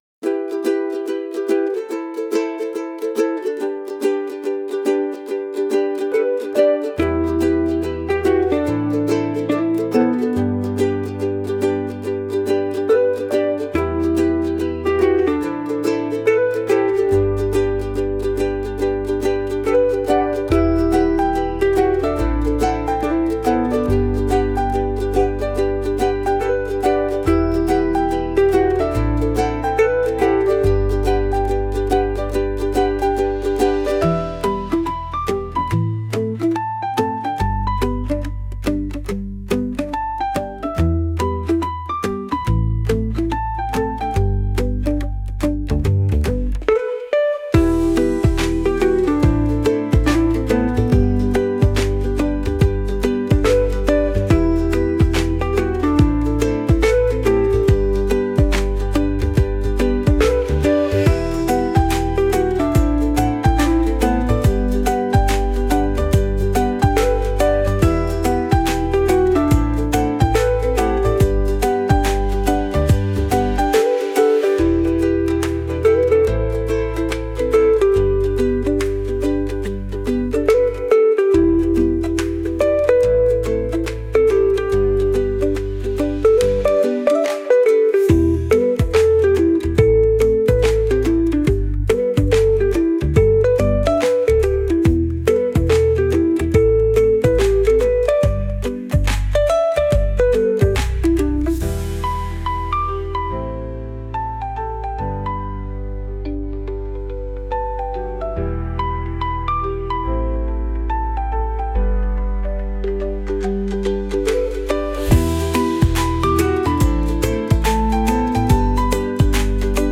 결과물은? 딱 종달새처럼 경쾌하고 발랄한 오케스트라 곡(120 BPM), 방울과 우쿨렐레를 사용한 완벽한 조화를 이루는 곡이 탄생했어요.